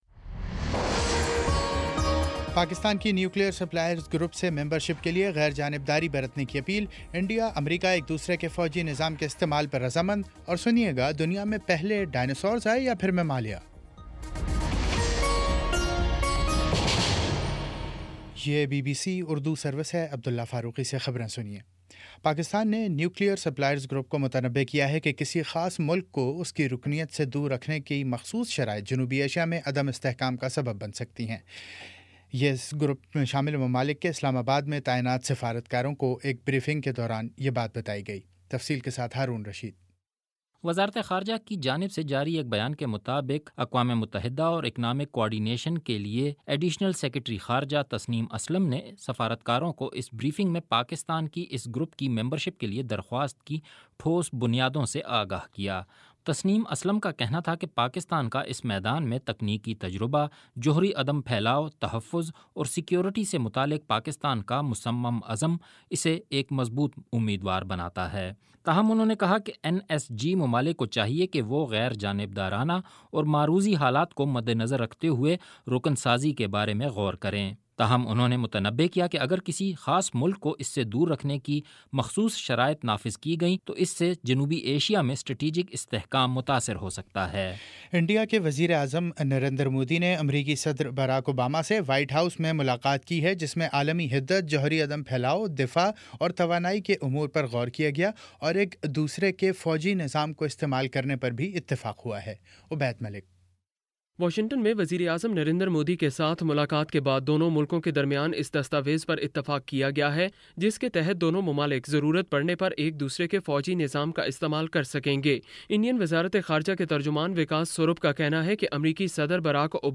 جون 08 : شام پانچ بجے کا نیوز بُلیٹن